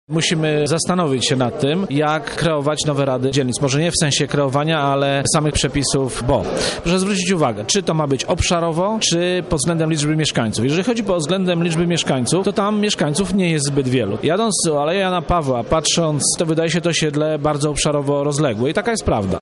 Szczegóły zdradza radny Zbigniew Ławniczak z Prawa i Sprawiedliwości.